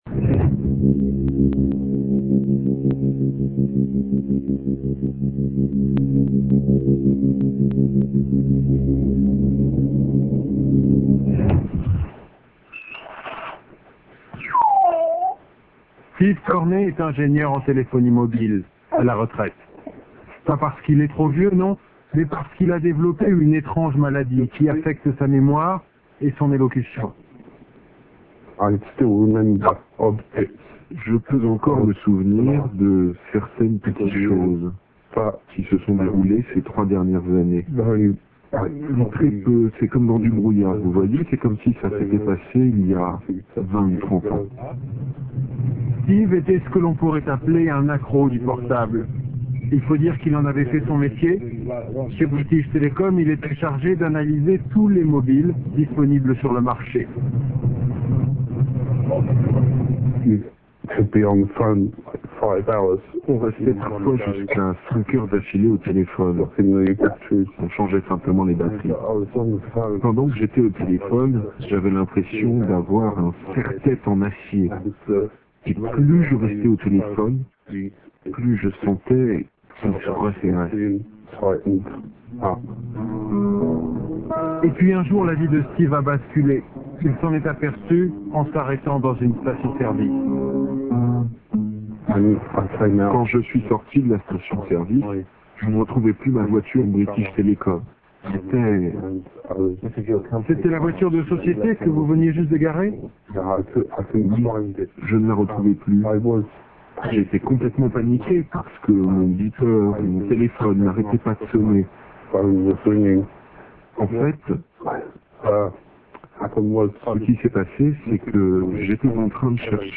Témoignage Utilisateur Téléphone Mobile